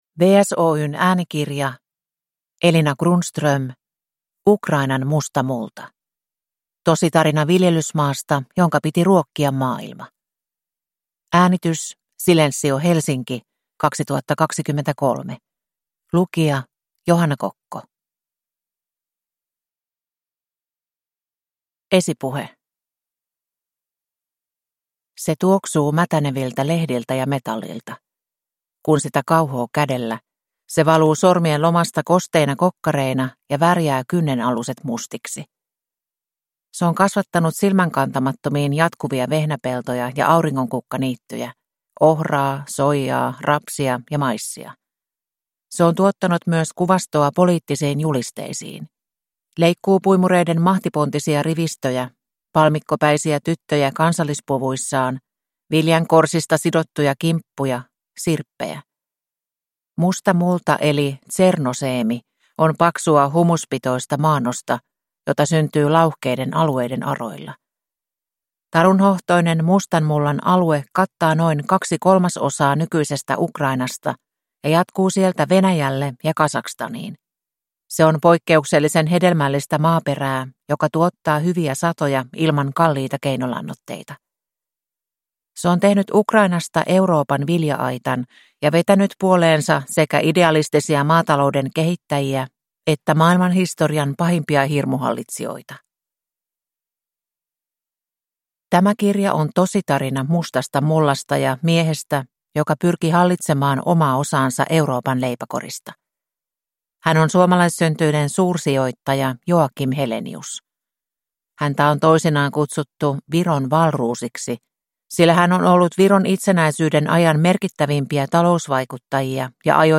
Ukrainan musta multa – Ljudbok